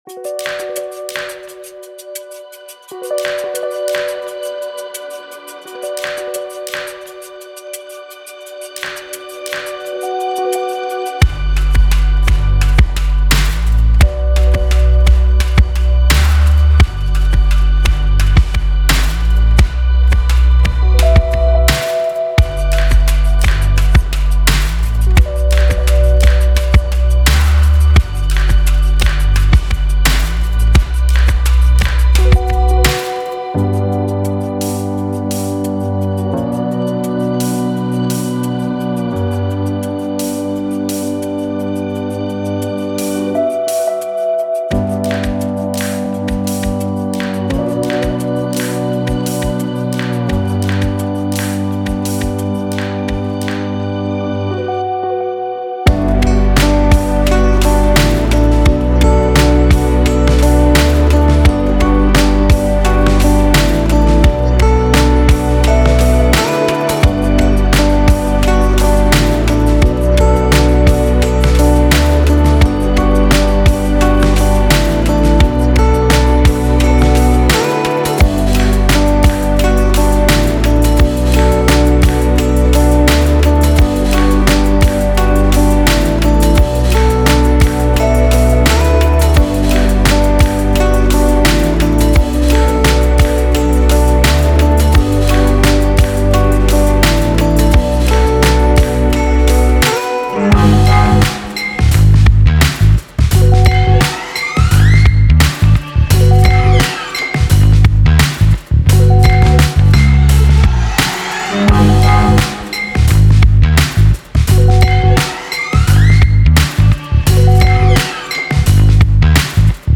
Genre:Indie Pop
500を超えるシンセ、パーカッション、ギター、FX、ドラム、ベースのサンプルが収録されています。
インディーらしさを演出するスナッピーなクラップ。
タイトなリズムから開放的でエアリーな質感まで対応するクローズドハイハットとオープンハイハット。
ビートを力強く前進させるパンチの効いたパワフルなキック。
デモサウンドはコチラ↓